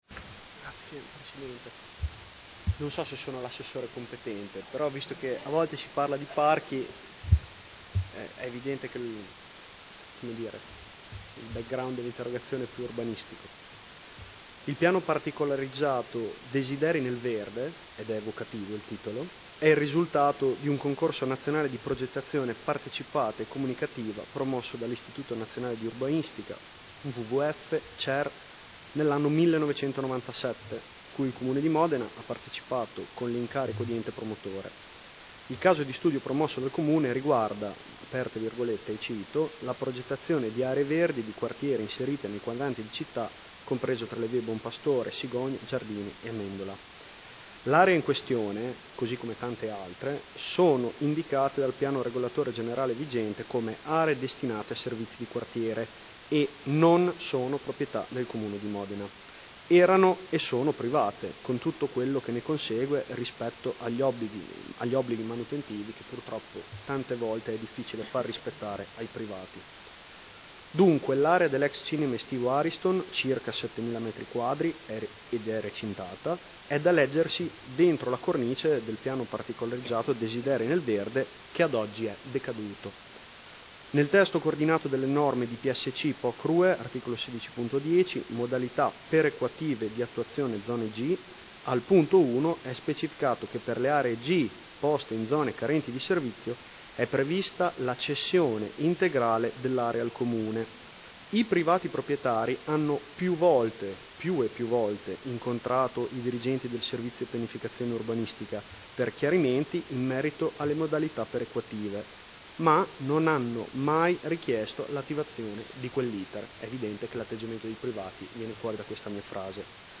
Seduta del 15 ottobre. Interrogazione del Gruppo consiliare Per Me Modena avente per oggetto: Quanto ancora si dovrà aspettare il parchetto promesso in Via Guarino Guarini adiacente all’ex cinema estivo Ariston?.